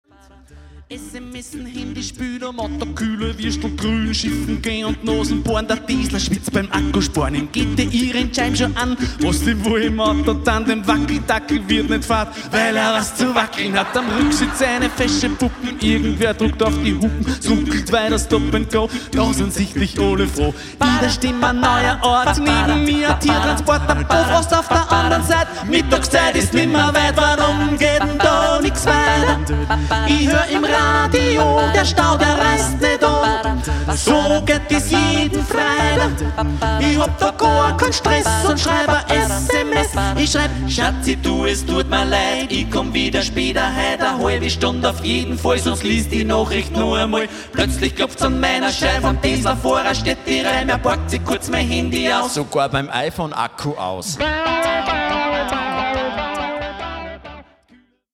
die LIVE-CD zum gleichnamigen Programm